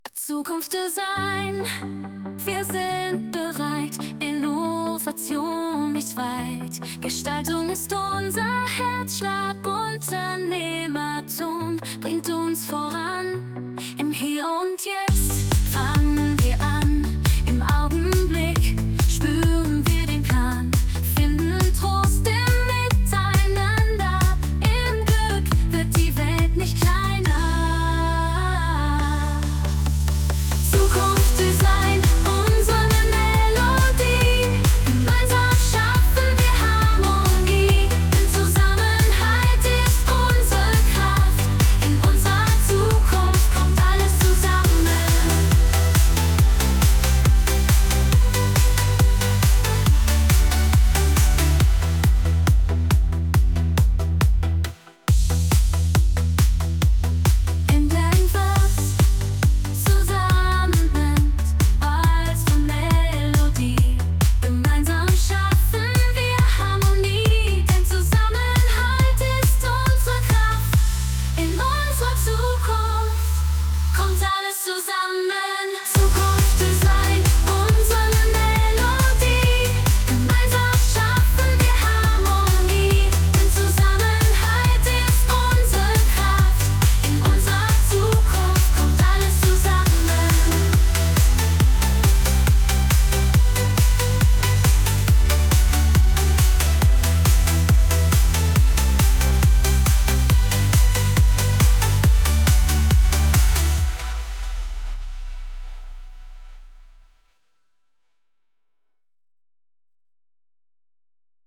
Schlager-Version
Schlager_ZukunftsDesign_Sonng.mp3